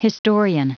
Prononciation du mot historian en anglais (fichier audio)
Prononciation du mot : historian